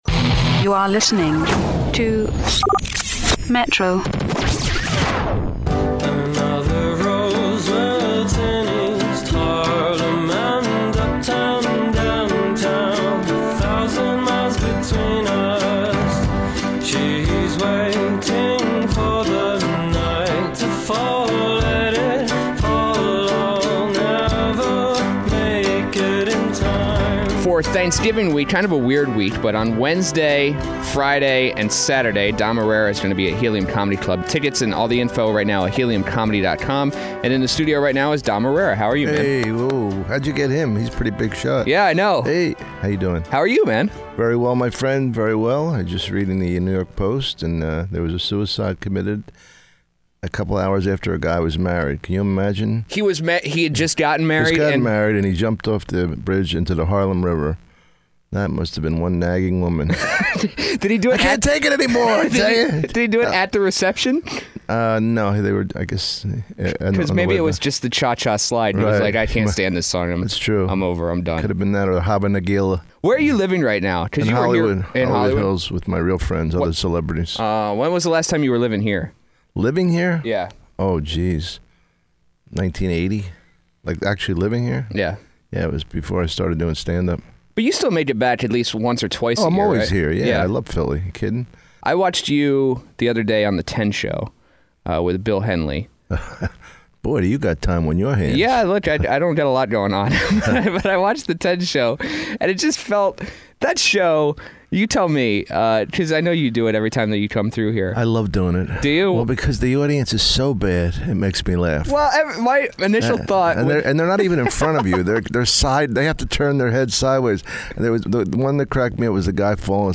Philadelphia’s favorite comedian Dom Irrera stopped by this morning.